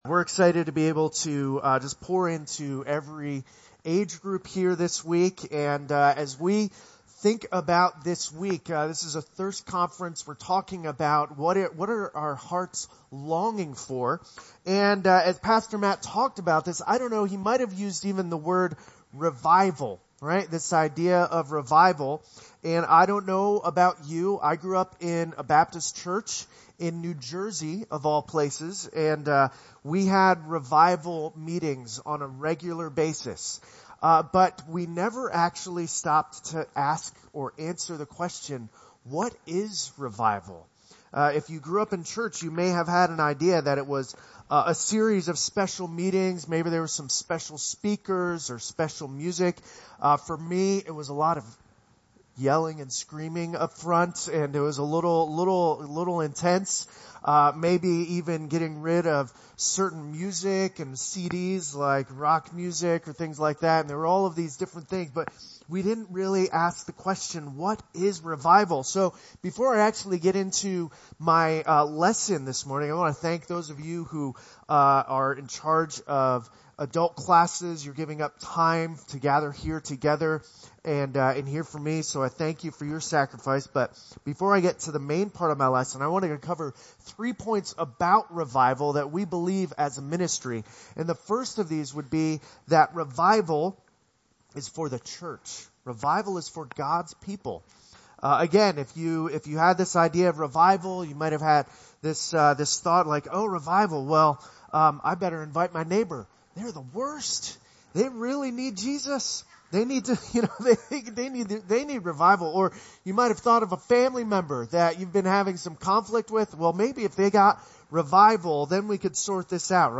Oak Grove Church Sermons